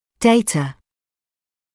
[‘deɪtə][‘дэйтэ]данные; информация; сведения